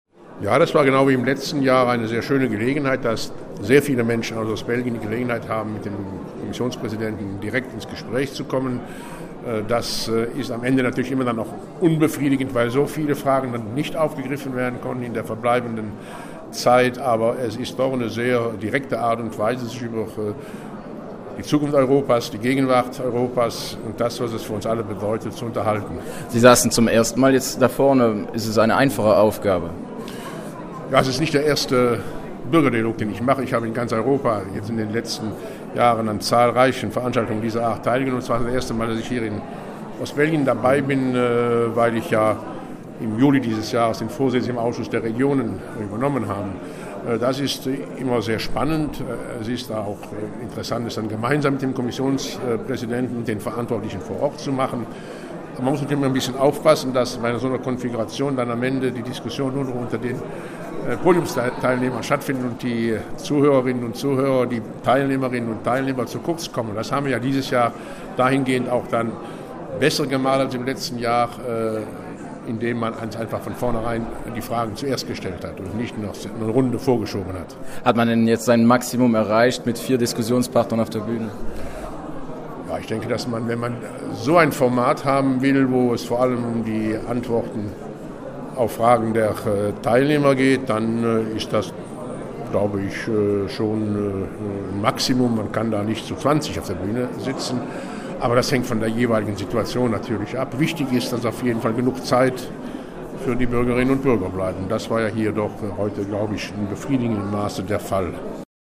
Senator Karl-Heinz Lambertz zog ebenfalls ein positives Fazit: